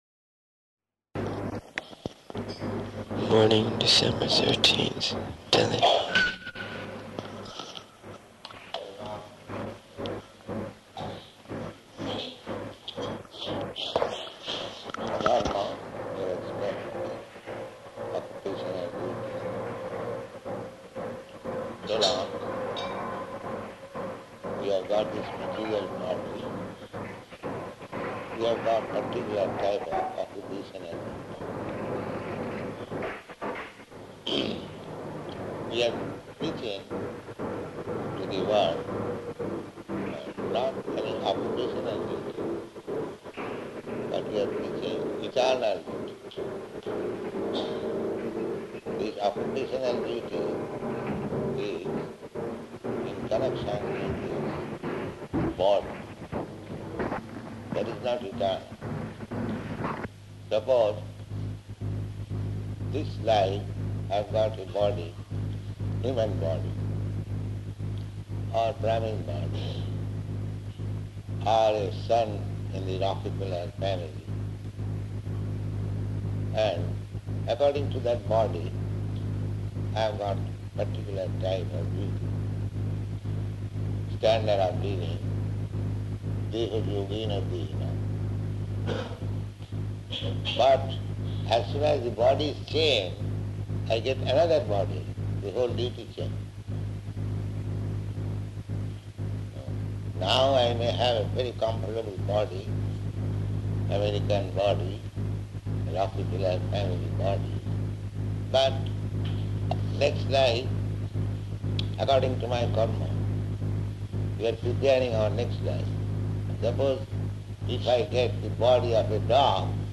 Lecture
Lecture --:-- --:-- Type: Lectures and Addresses Dated: December 13th 1971 Location: Delhi Audio file: 711213LE-DELHI.mp3 [Noisy upto 4:30] Devotee: [introducing recording] Morning, December 13th, Delhi.